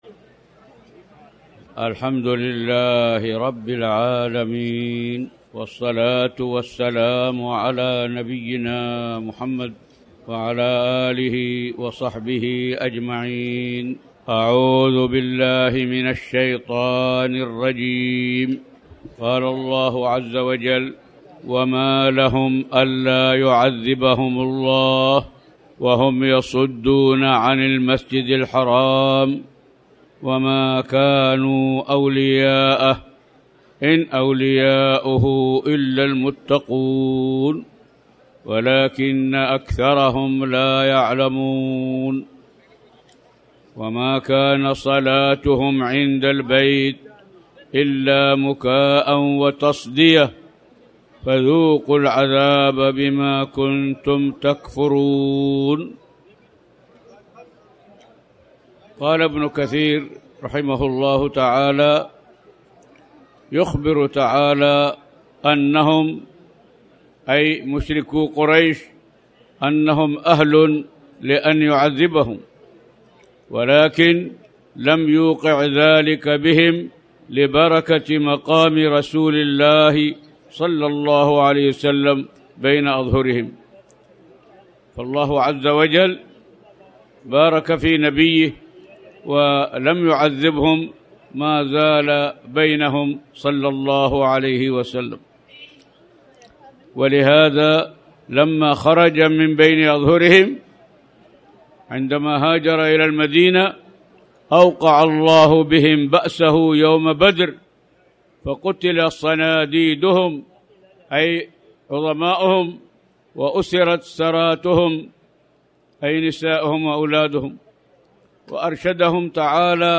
تاريخ النشر ٢٥ شعبان ١٤٣٩ هـ المكان: المسجد الحرام الشيخ